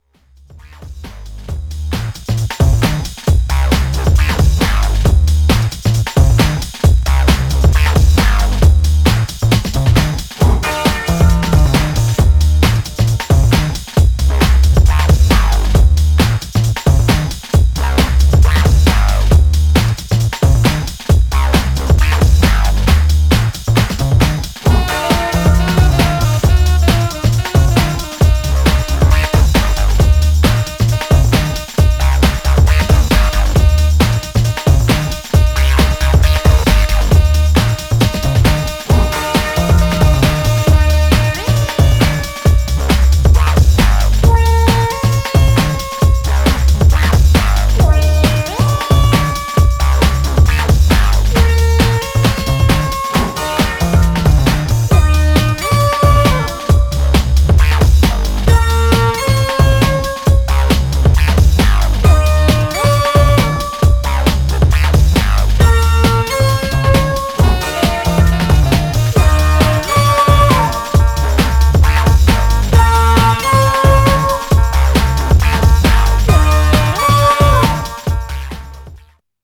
Styl: Drum'n'bass, Breaks/Breakbeat